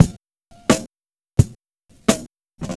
Track 02 - Kick Snare Beat 02.wav